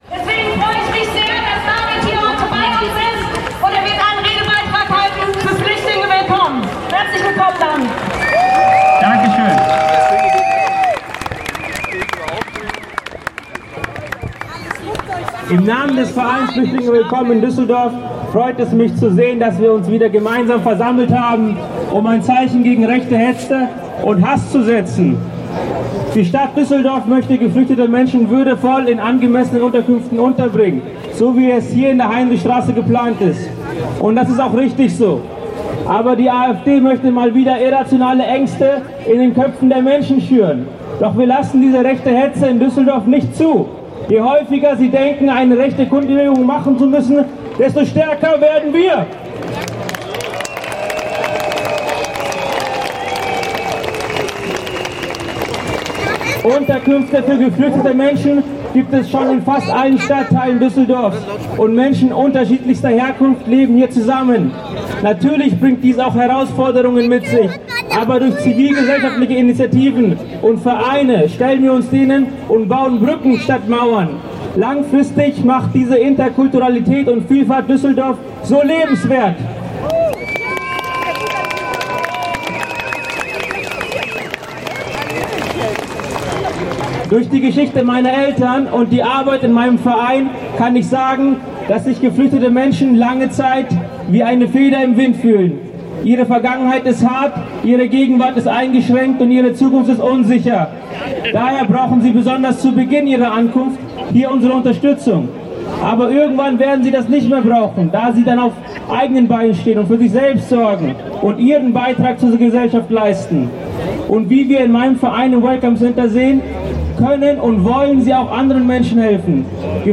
Kundgebung „Düsseldorf stellt sich quer gegen extreme Rechte und Rassismus!“ (Audio 3/7)